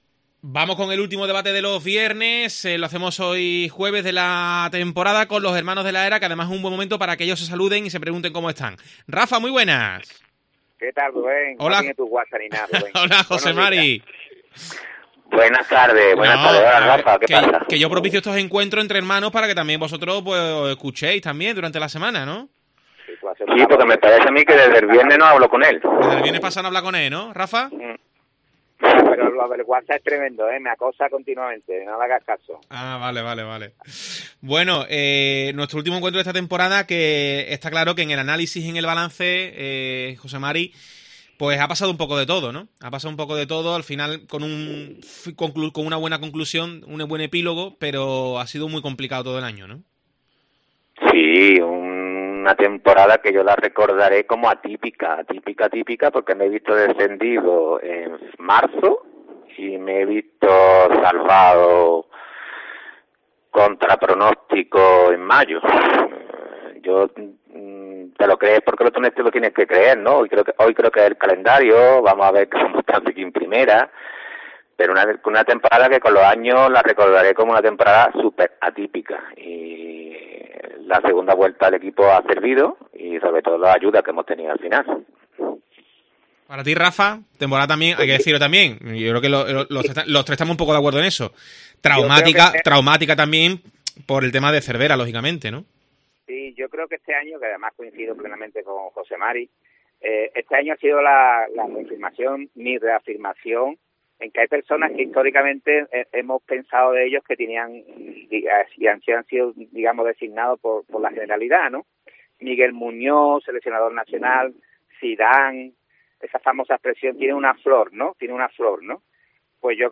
El debate de Deportes COPE sobre el Cádiz CF